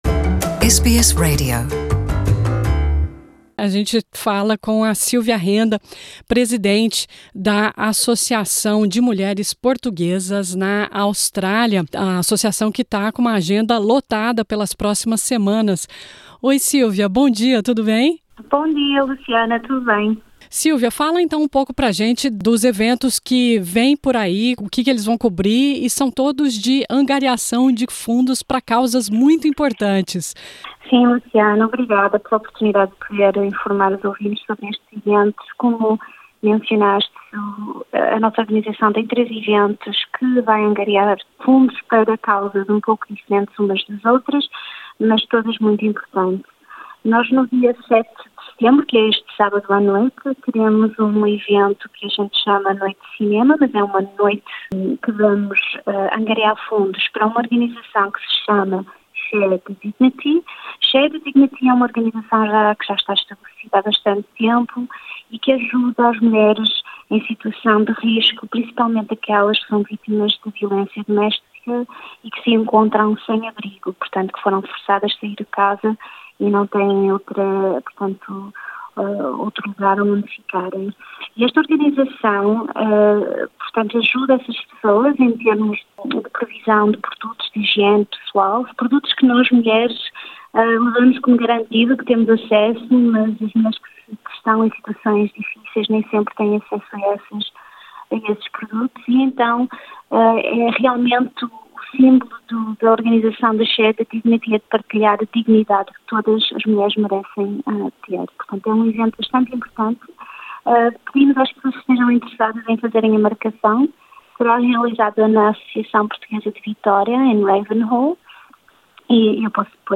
Nessa entrevista ela também convida para a Olivia Newton John Wellness Run cuja renda será revertida para a fundação do Cancro e Bem estar da Olivia Newton – John e também para o evento White Ribbon de apoio às vítimas da violência doméstica. Ela também fala da AMPA que começou em Vitória mas que, com o apoio das portuguesas, está abrindo sedes em outros estados australianos e auxiliando as mulheres de todo país.